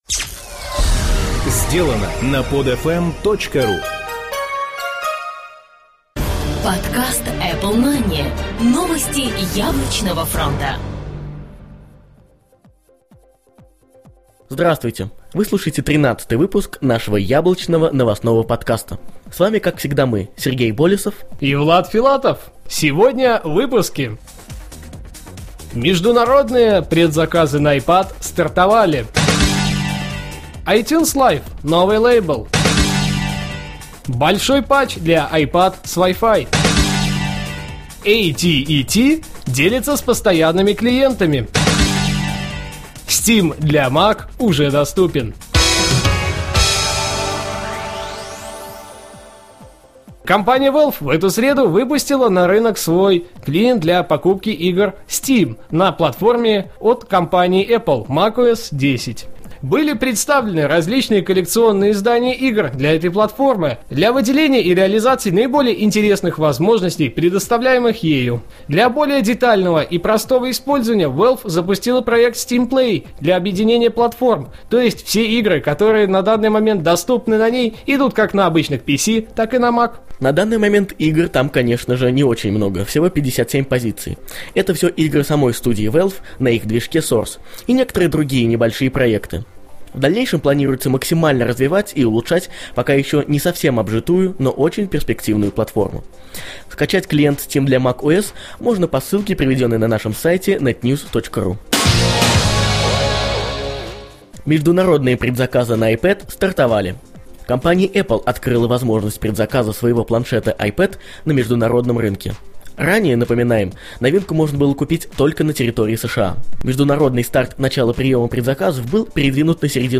"Apple Mania" - еженедельный новостной Apple подкаст
Жанр: новостной Apple-podcast